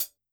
soft-slidertick.wav